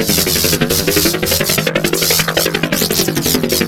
alien_rotor_01.ogg